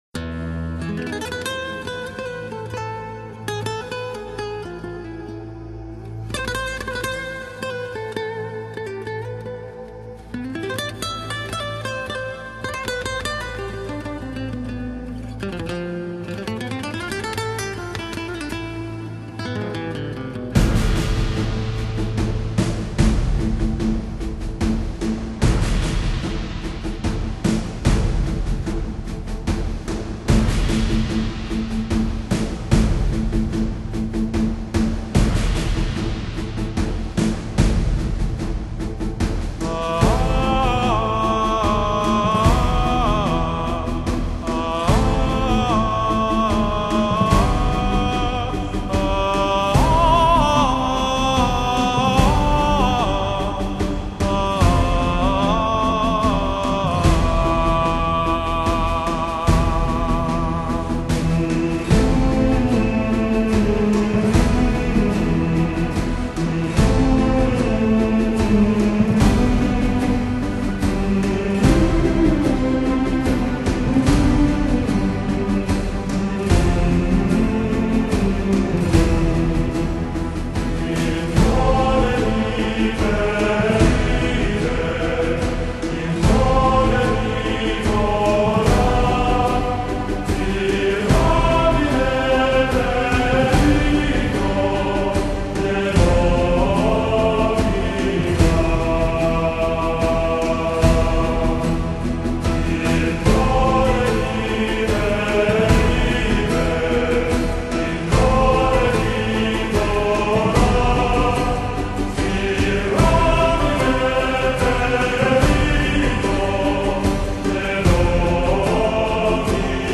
Genre: Symphonic Rock, New Age